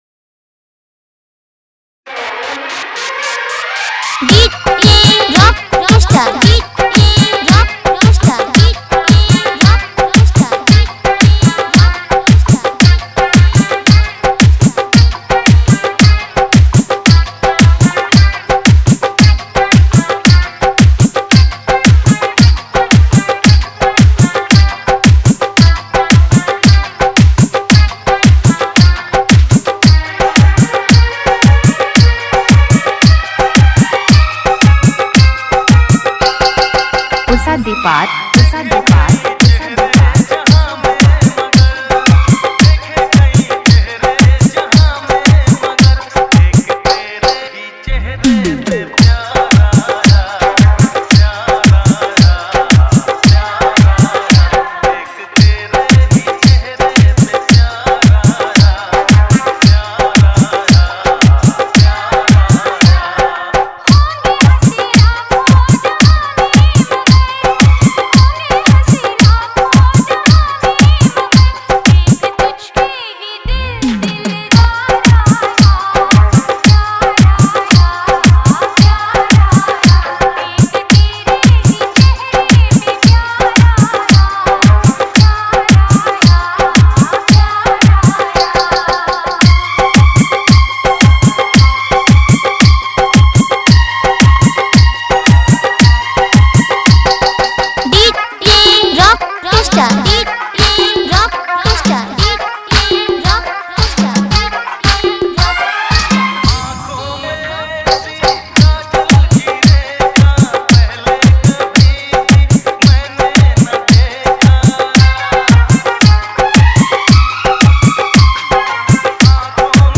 Category:  Love Dj Remix